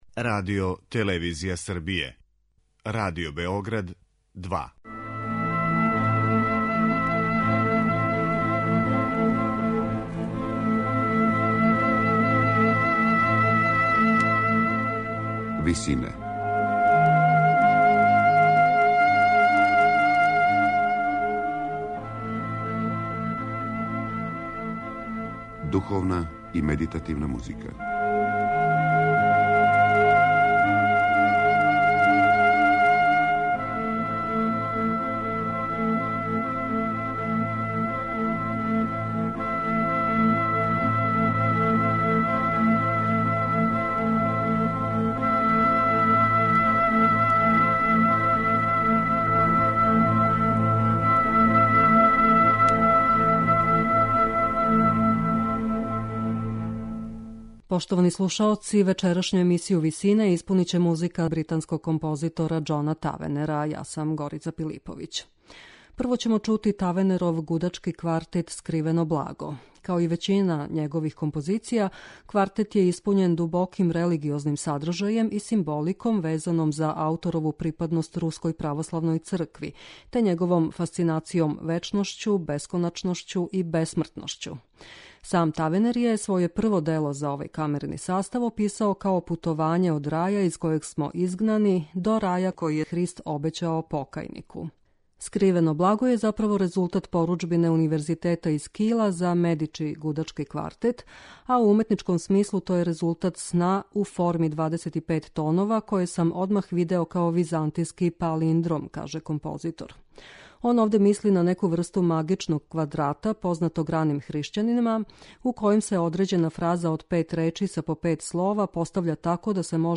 медитативне и духовне композиције
гудачки квартет